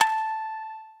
shamisen_a.ogg